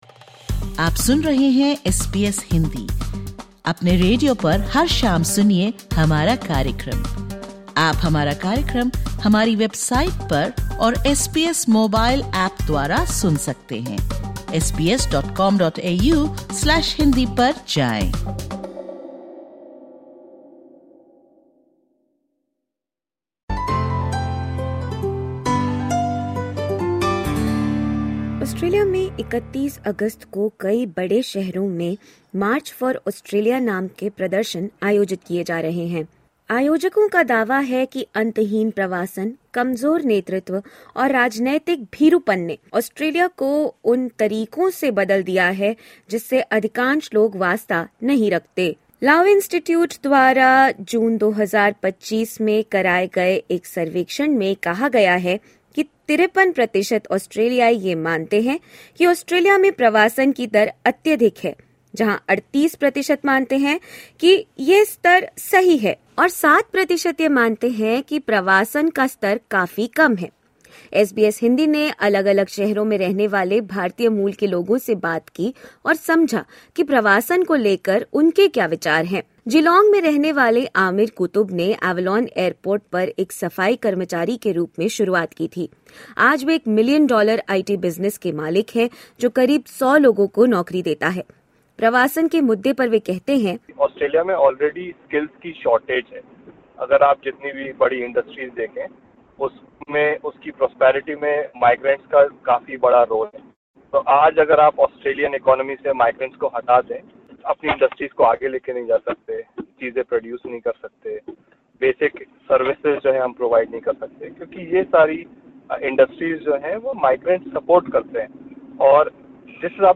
Tune in to this podcast featuring members of the Indian community as they share why migration is vital for Australia.